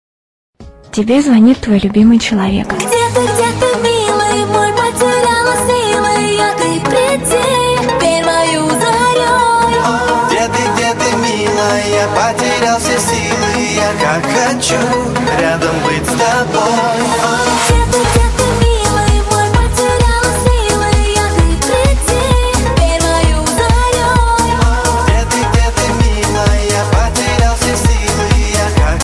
• Качество: 128, Stereo
поп
громкие